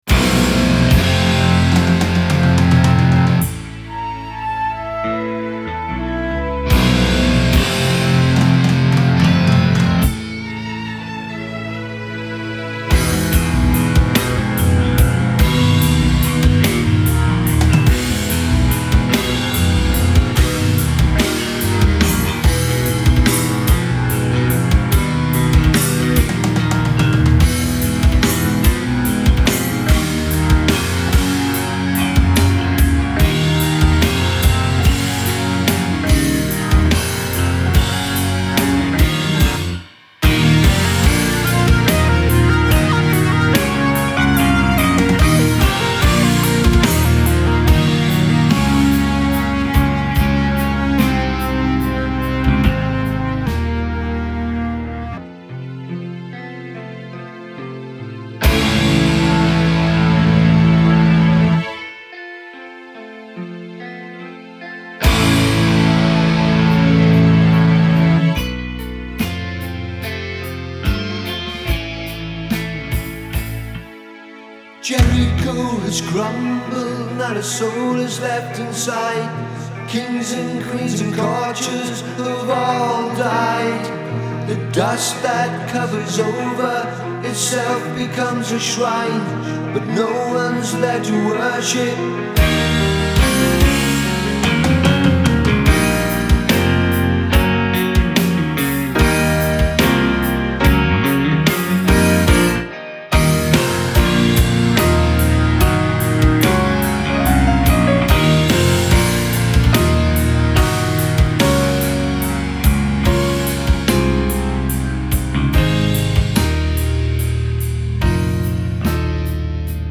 keyboards/drums
bass/lead vocals
guitars/backing vocals
It's a dark album that draws you in, and holds you there…